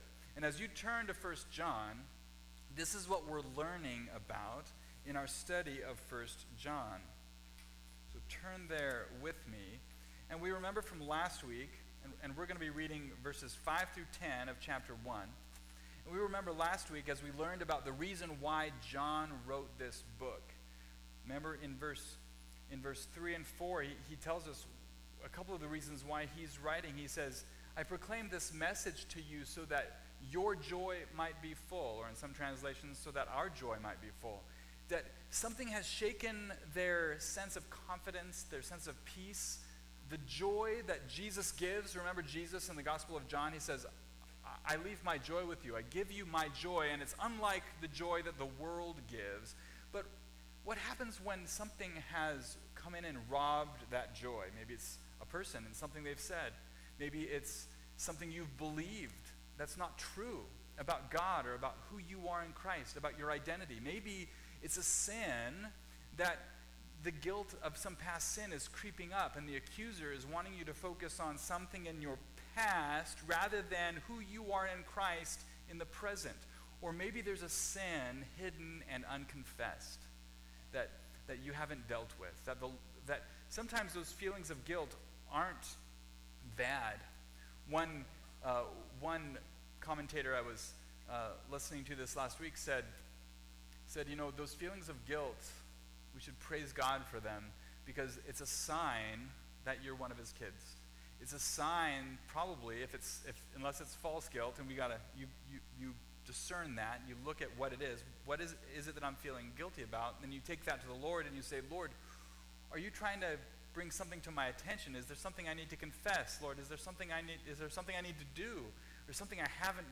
Walk In The Light (1st John 1:5-10) – Mountain View Baptist Church